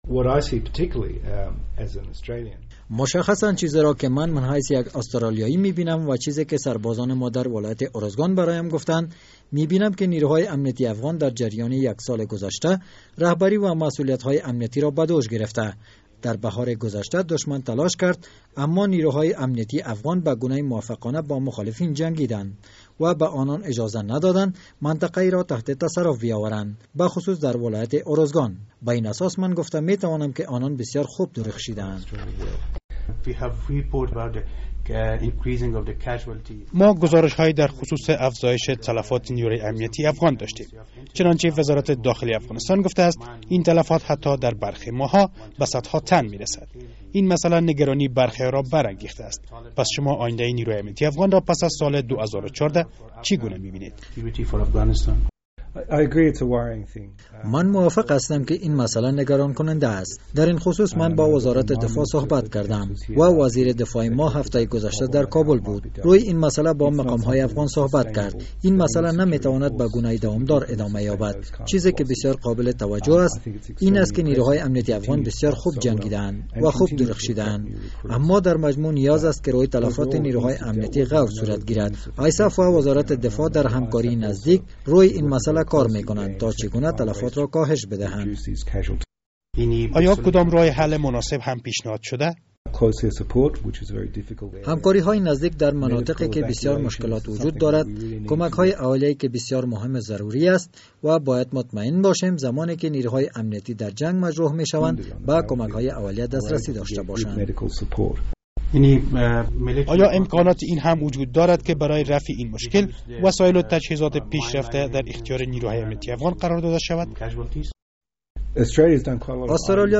مصاحبهء اختصاصی با سفیر آسترالیا در کابل